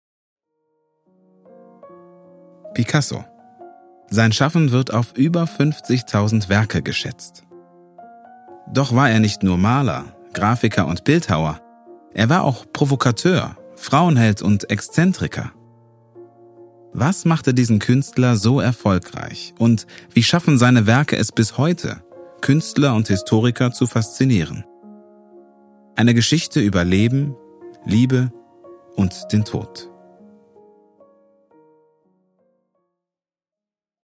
Voiceover
Meine stimmcharakteristik ist warm, klar und nahbar.
5-Voiceover-Doku.mp3